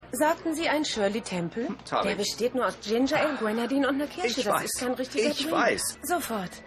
Ed_1x12_Kellnerin.mp3